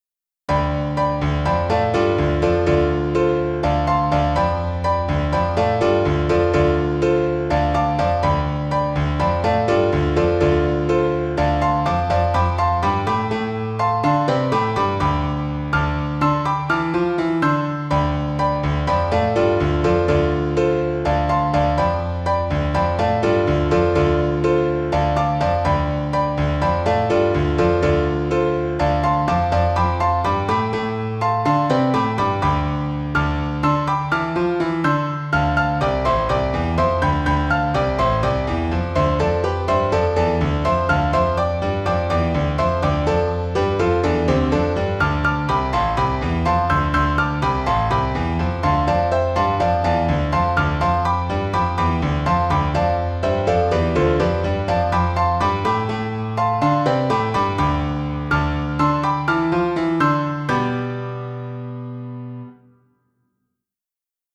PIANO H-P (34)